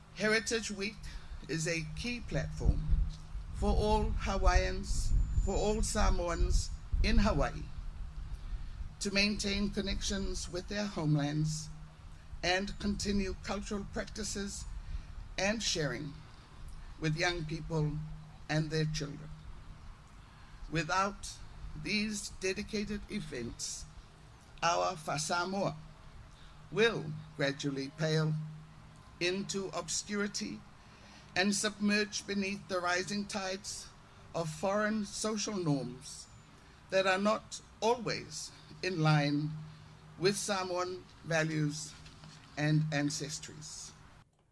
Samoa Prime Minister Fiame Naomi Mataafa was the keynote speaker. She offered congratulations to Governor Green for hosting a successful Pacific Arts Festival, and lauded Governor Lemanu and the American Samoa Government for helping instill pride in the Samoan culture and heritage among Samoans living in Hawaii.